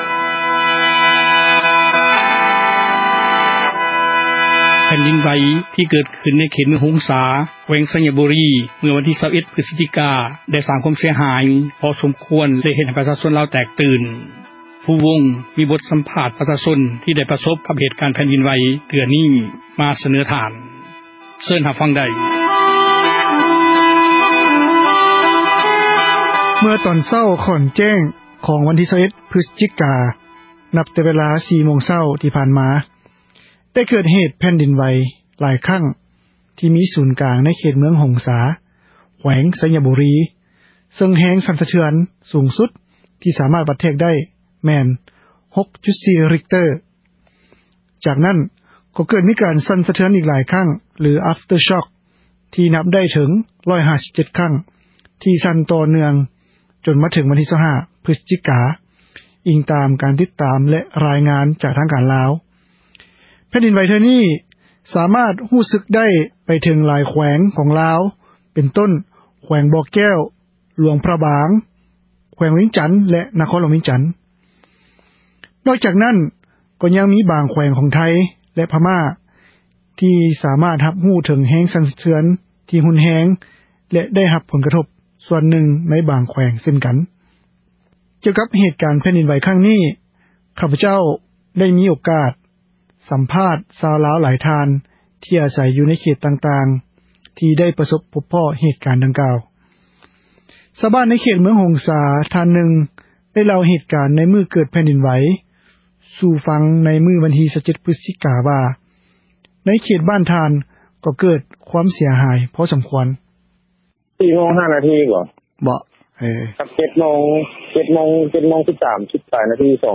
ກ່ຽວກັບເຫຕການແຜ່ນດິນໄຫວຄັ້ງນີ້, ຂພຈ ໄດ້ມີໂອກາດ ສັມພາດຊາວລາວຫຼາຍທ່ານ ທີ່ອາໃສຢູ່ໃນເຂດຕ່າງໆ ທີ່ໄດ້ປະສົພພົບພໍ້ເຫຕການດັ່ງກ່າວ.